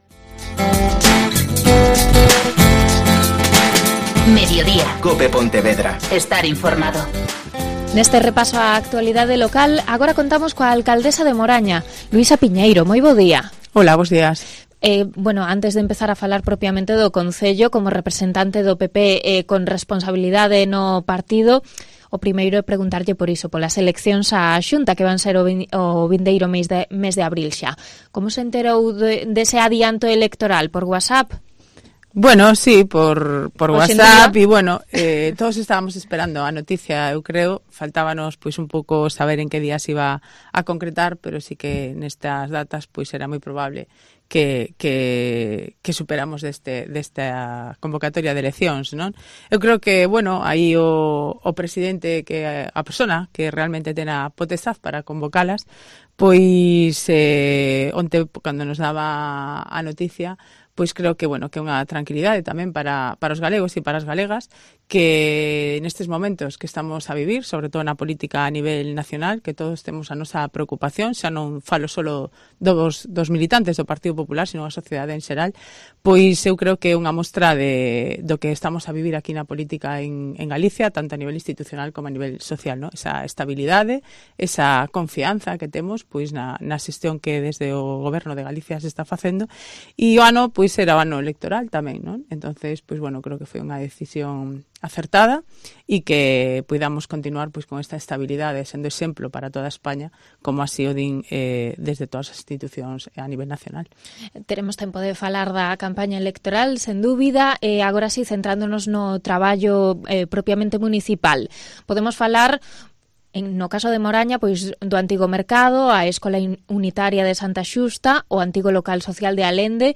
Entrevista con la alcaldesa de Moraña, Luisa Piñeiro